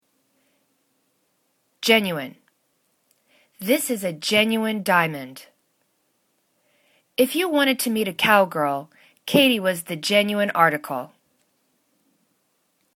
gen.u.ine    /'djenjwәn/     adj